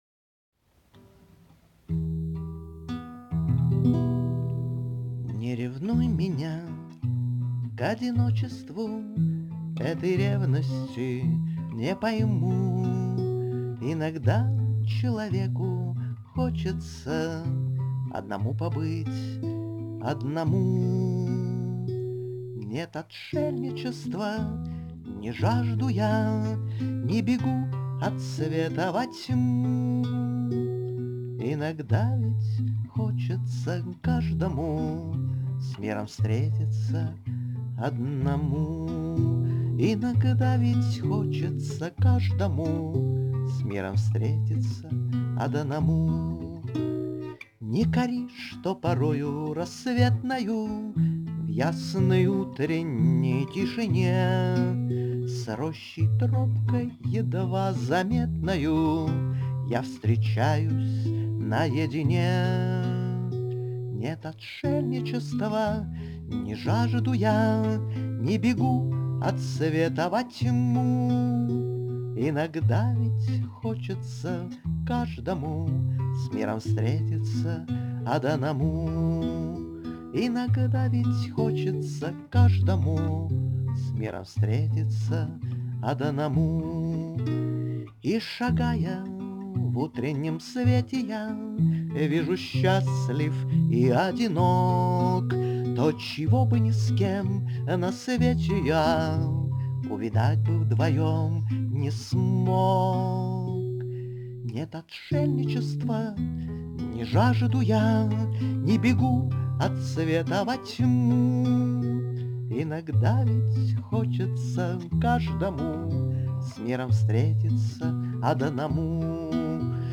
Пение, гитара